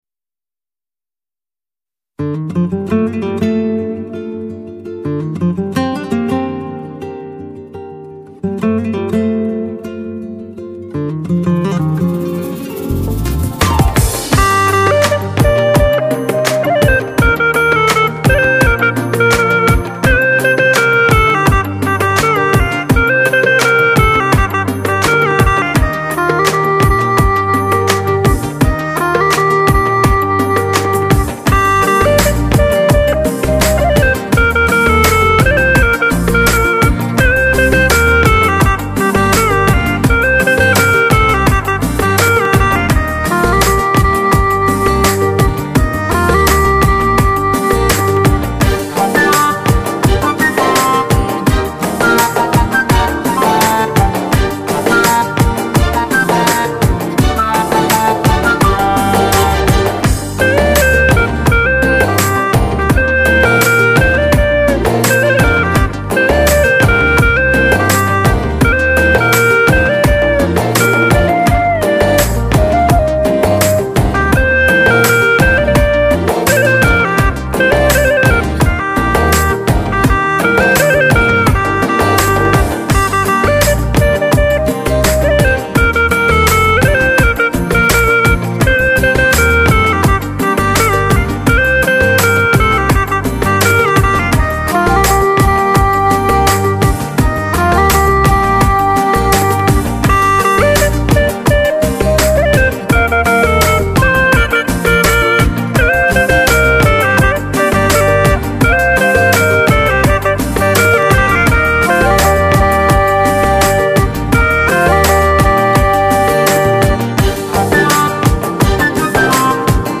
民族乐器葫芦丝搭配印巴风情的曲子,别有一番风味
当国乐染上印巴情调,让悠扬热情的旋律带我们走进这神秘的异国
携一支葫芦丝带你畅游,在音乐世界里,体会最真实、最古老的印度